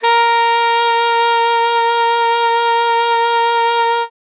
42c-sax08-a#4.wav